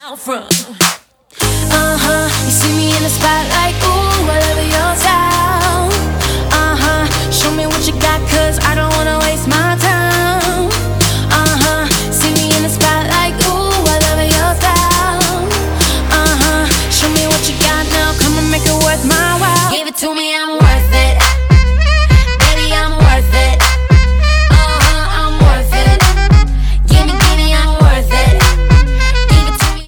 • Pop Ringtones